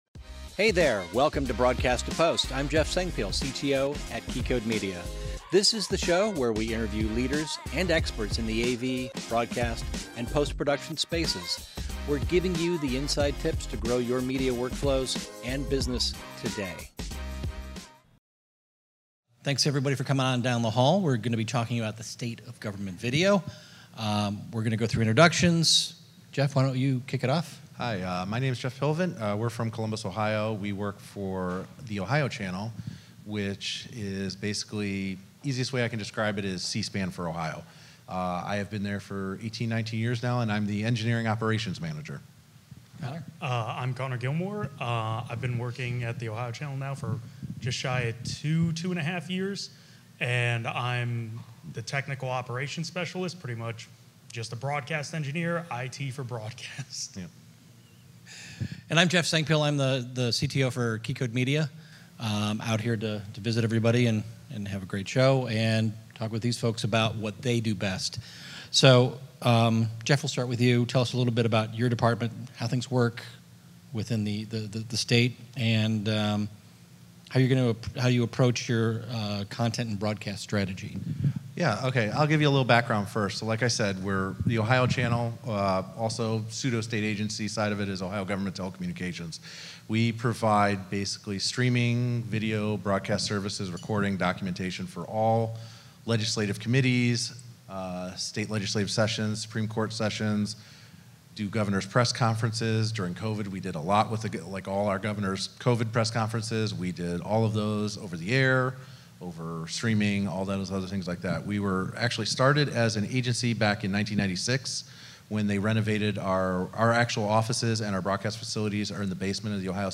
This is a live recording from our PostNAB Detroit event.